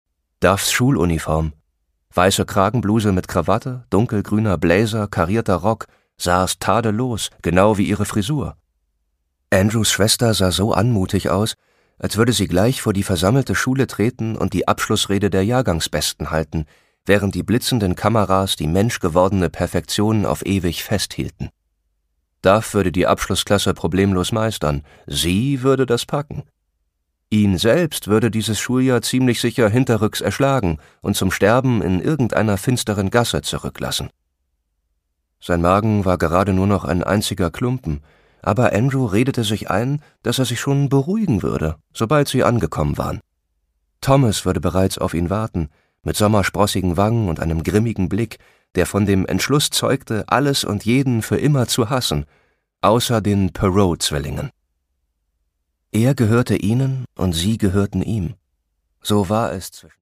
Produkttyp: Hörbuch-Download
erzählt melancholisch verträumt und düster fesselnd das romantische Schauermärchen. Dabei lotet er die psychologischen Tiefen gekonnt und einfühlsam aus.